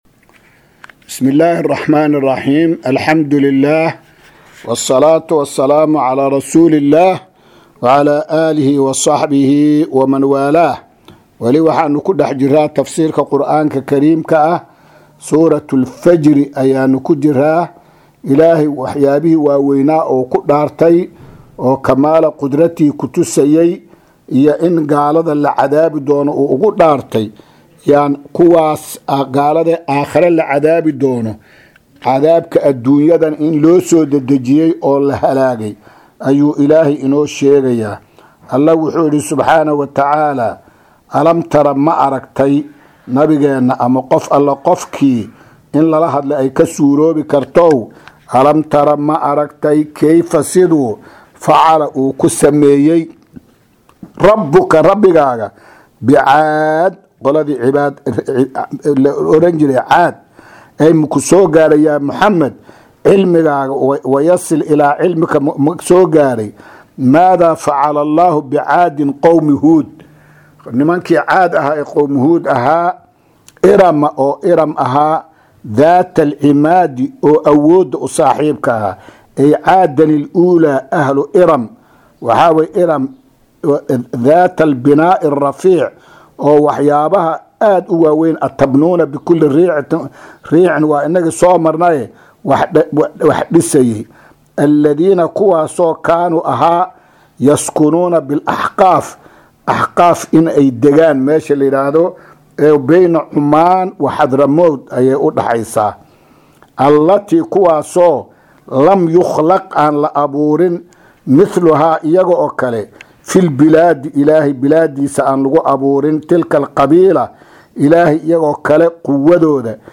Maqal:- Casharka Tafsiirka Qur’aanka Idaacadda Himilo “Darsiga 285aad”